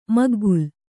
♪ maggul